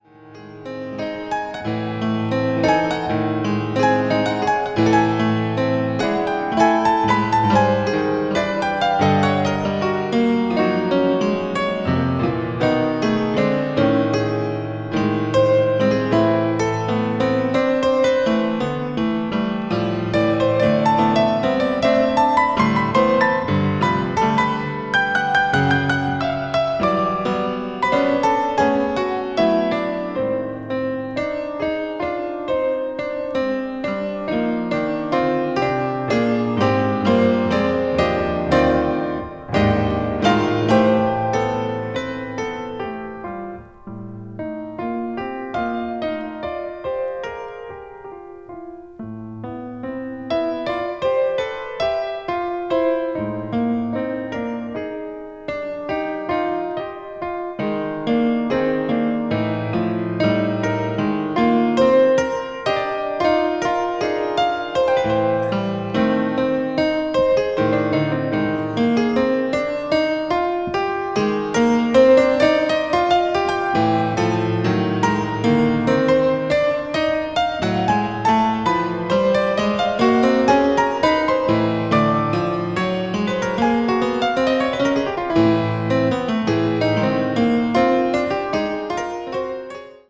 contemporary jazz   piano solo   spiritual jazz